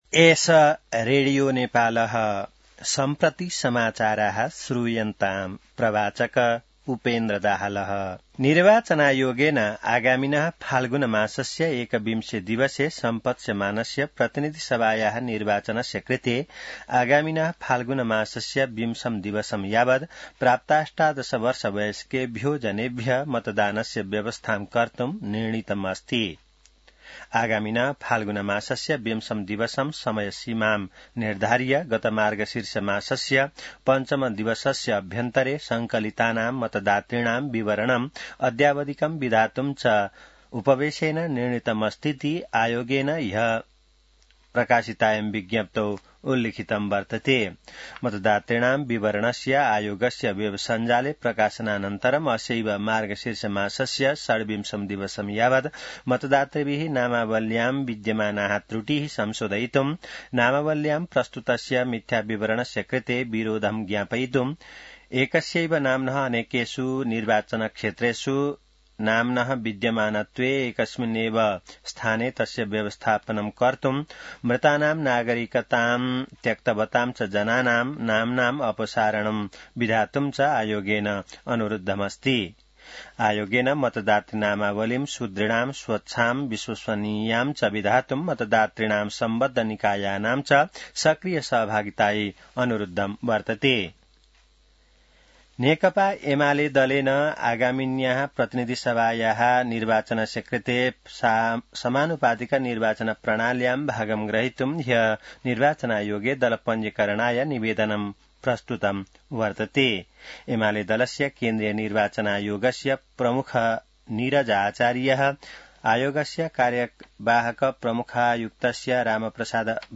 An online outlet of Nepal's national radio broadcaster
संस्कृत समाचार : २३ मंसिर , २०८२